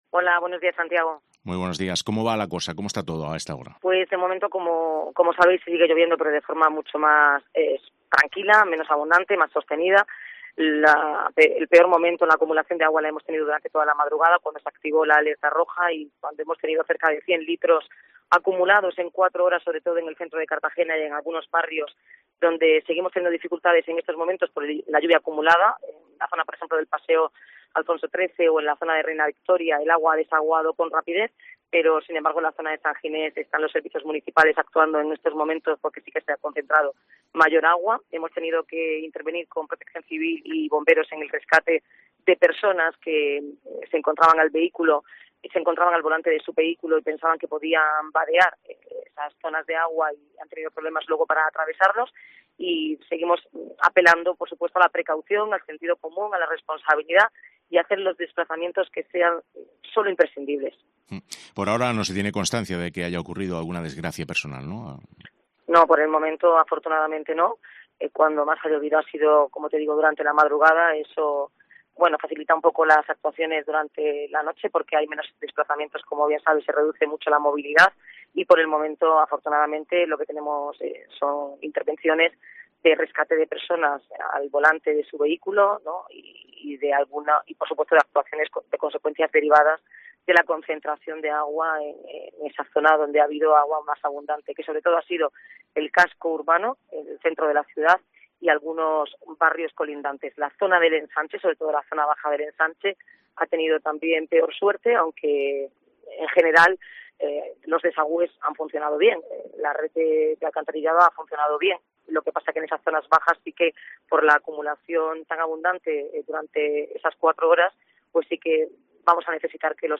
Entrevista matinal a Noelia Arroyo sobre inundaciones